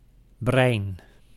Ääntäminen
IPA: [ˈbrɛɪn]